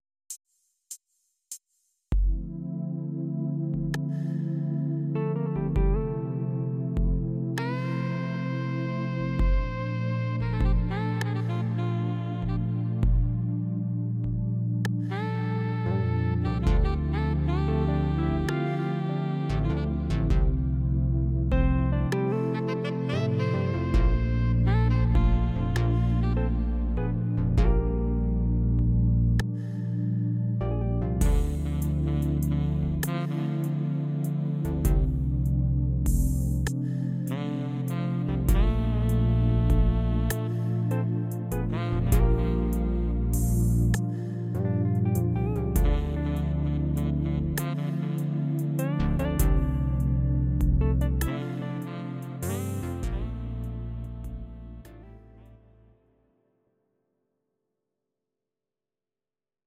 Audio Recordings based on Midi-files
Pop, Jazz/Big Band, 1990s